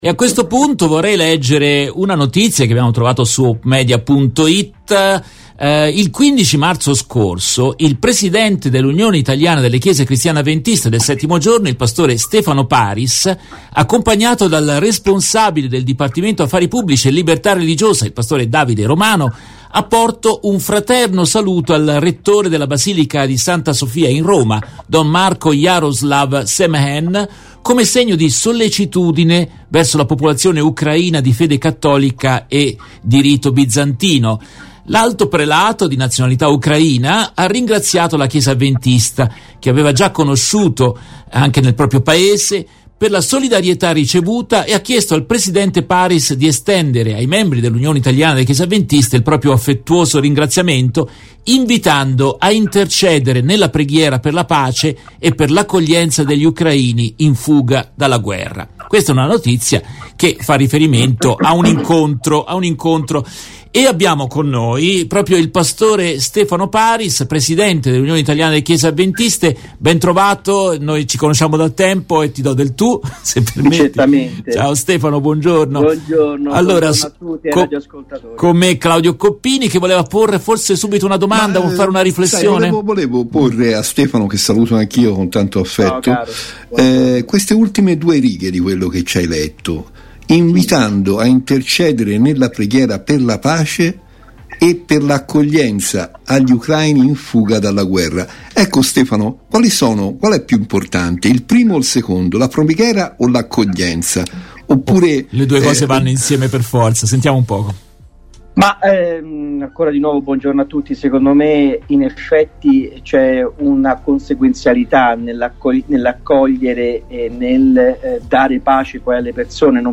hanno intervistato il pastore